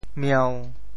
潮州发音 潮州 mieu1
mieu1.mp3